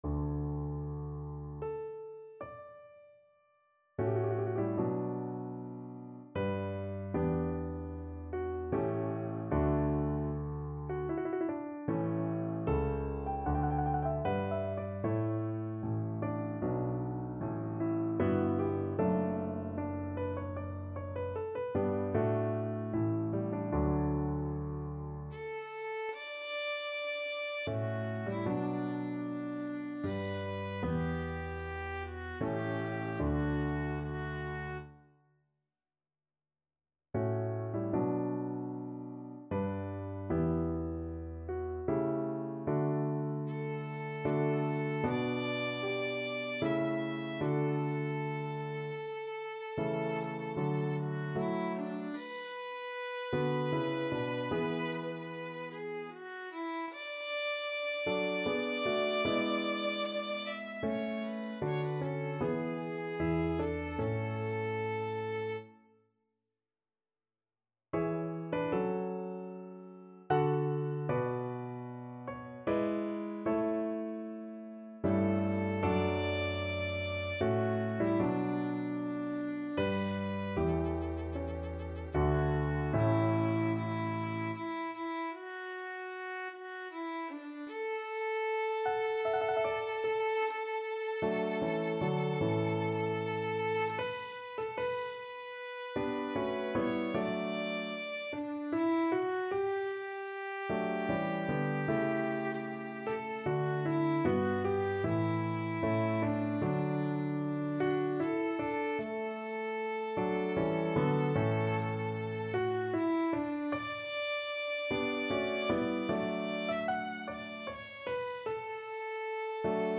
Larghetto (=76)
3/4 (View more 3/4 Music)